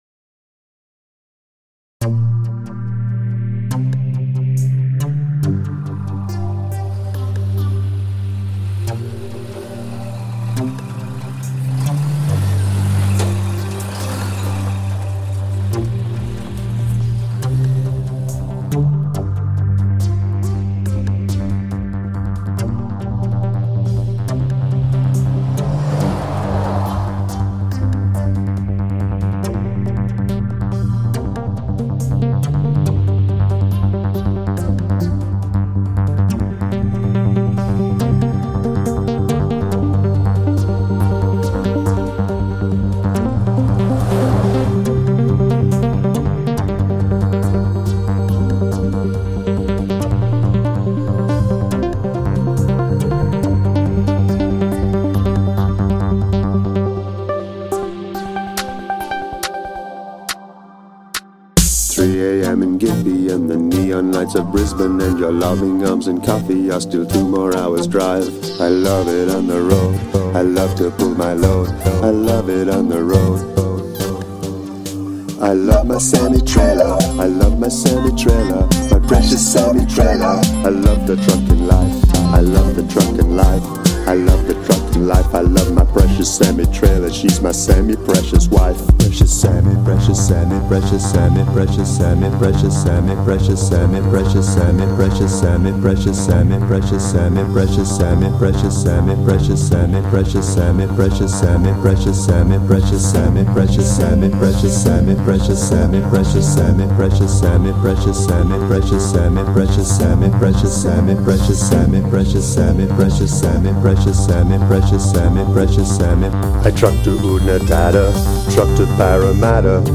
Gradual emphasis of repetitions
by Frankie and the Mountweasels
a full minute of intro is a bold choice. I like the synth arrangement though so I think you get away with it.